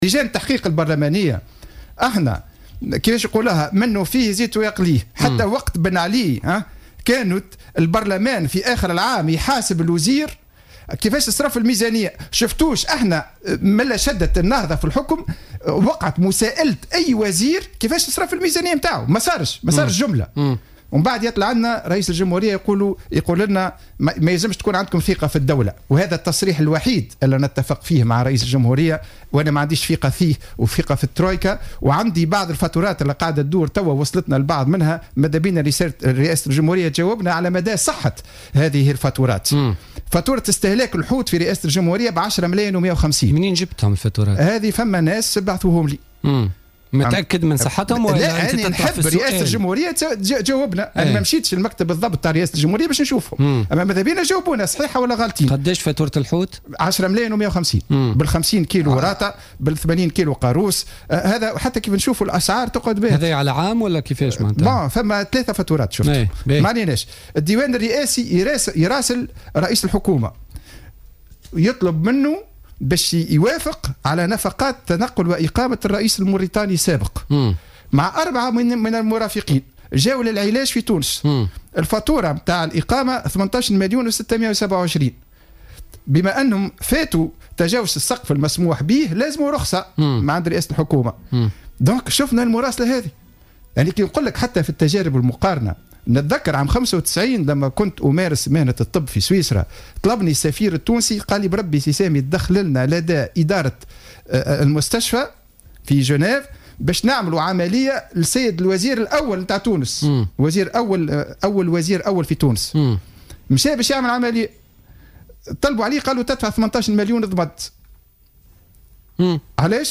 a dévoilé lors de son passage sur les ondes de Jawhara FM dans le cadre de Politica du mardi 25 novembre 2014, des factures de consommation de la présidence de la République.